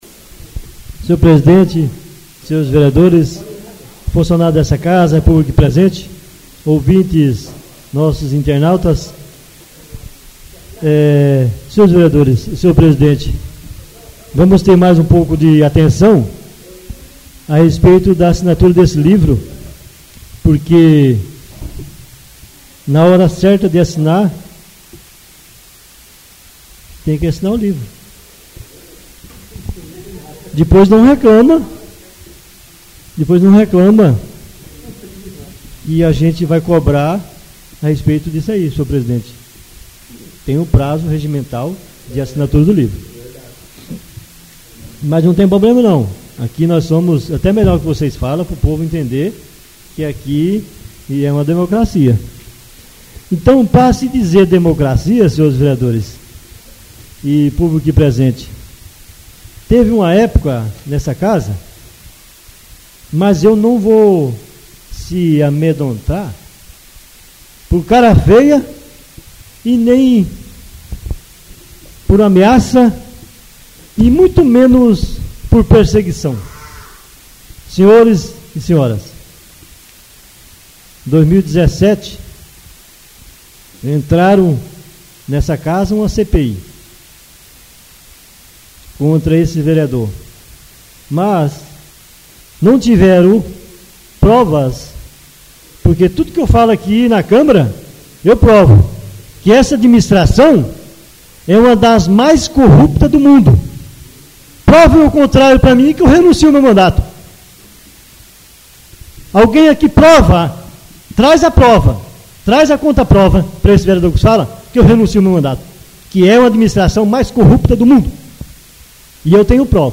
Oradores das Explicações Pessoais (22ª Ordinária da 3ª Sessão Legislativa da 6ª Legislatura)